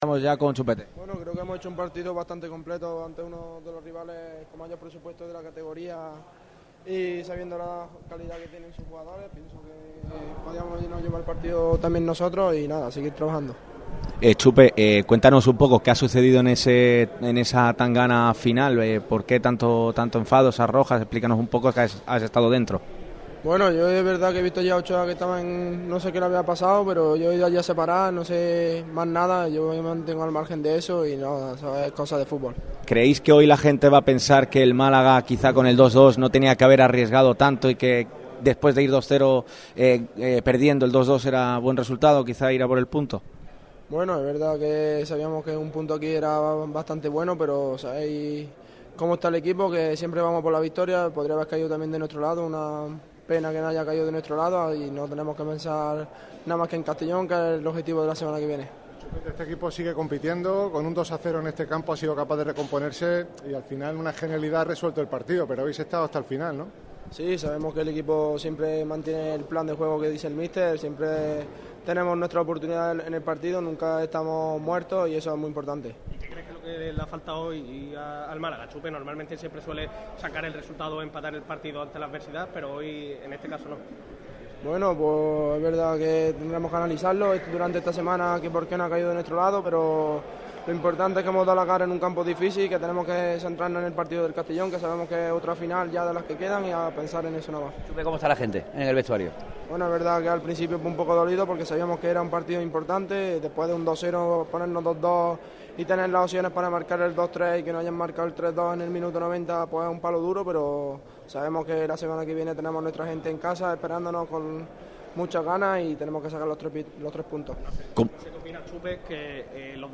El delantero cordobés compareció ante la prensa al término del partido que se saldó con derrota malaguista en el estadio del Almería (3-2).
Declaraciones de Chupete